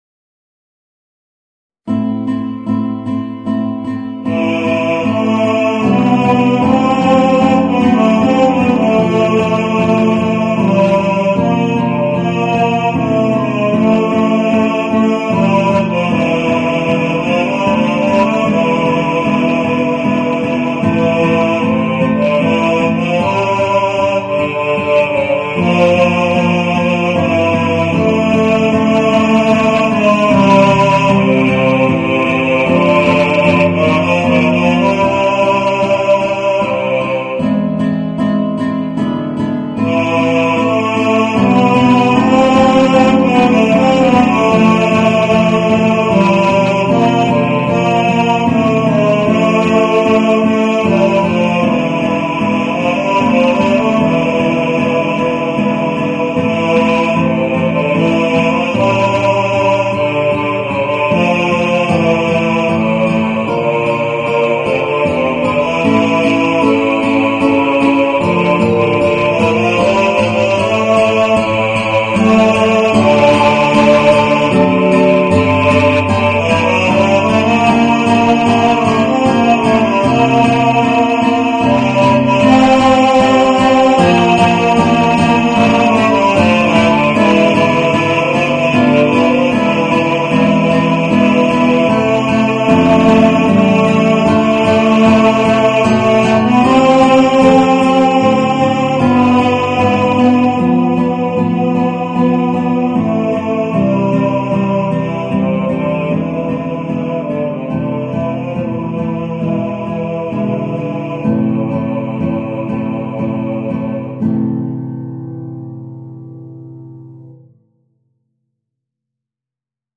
Voicing: Guitar and Bass